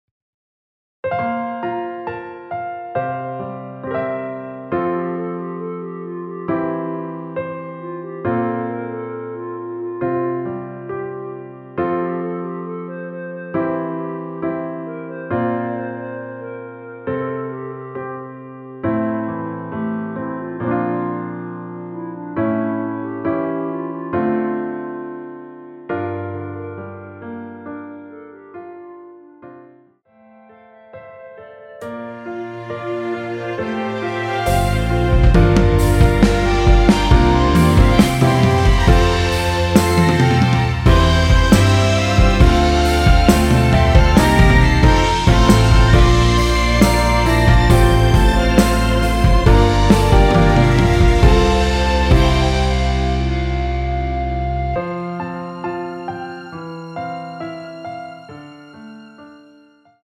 원키에서(+2)올린 멜로디 포함된 MR입니다.(미리듣기 확인)
멜로디 MR이라고 합니다.
앞부분30초, 뒷부분30초씩 편집해서 올려 드리고 있습니다.